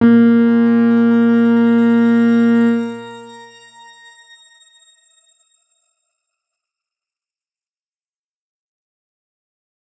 X_Grain-A#2-mf.wav